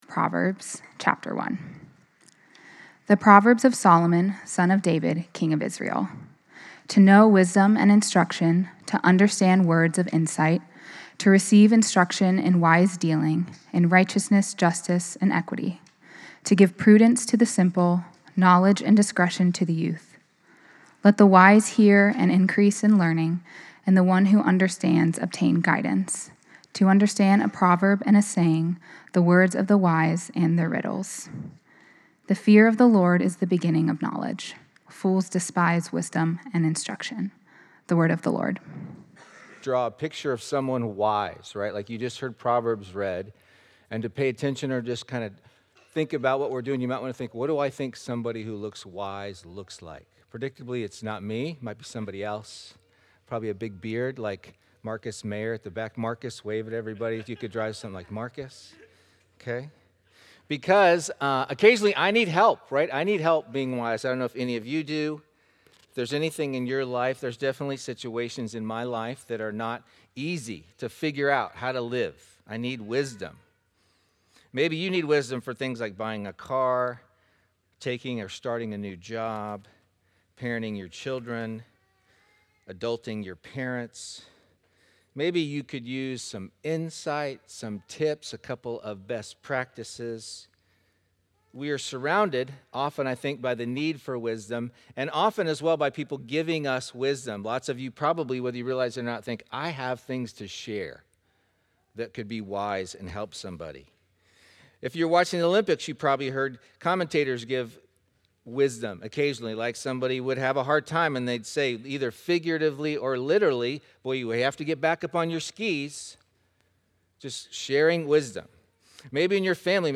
Proverbs 1: Biblical Wisdom Sermon - Christ Church Vienna